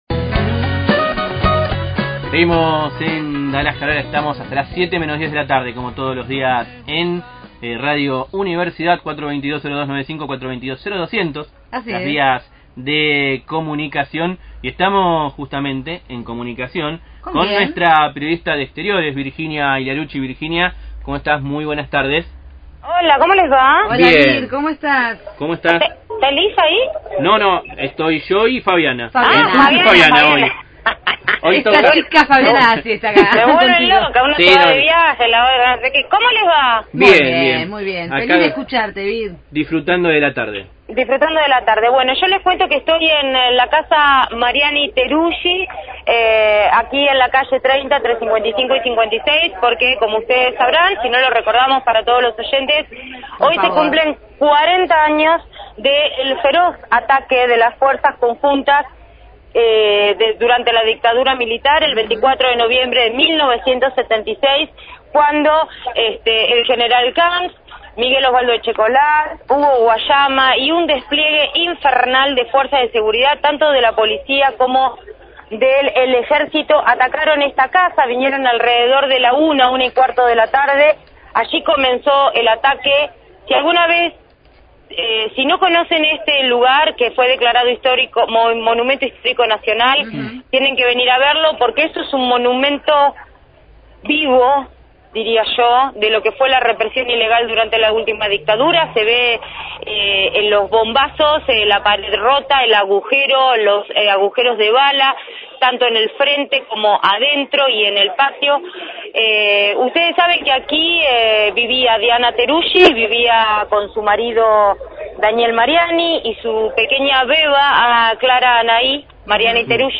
desde la casa Mariani-Teruggi con el aniversario del ataque durante la ultima dictadura militar. Programa: Darás que Hablar.